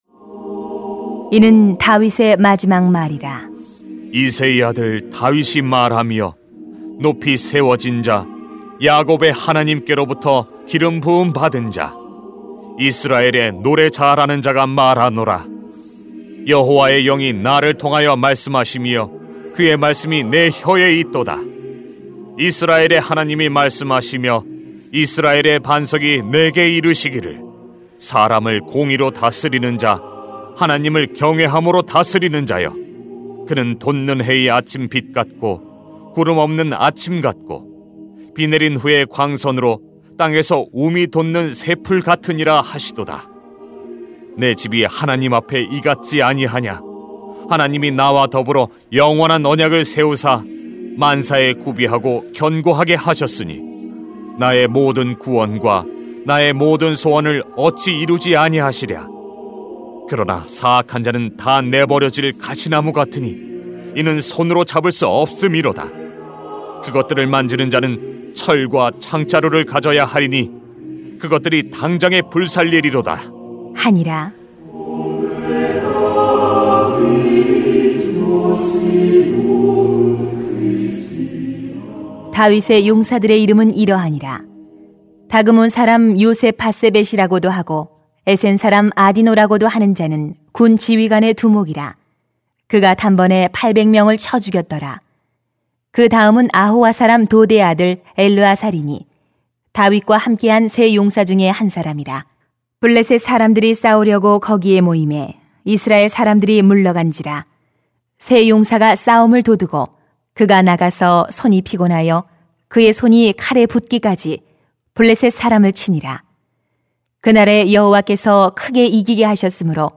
[삼하 23:1-17] 인생의 마지막 날 찬송을 부릅시다 > 새벽기도회 | 전주제자교회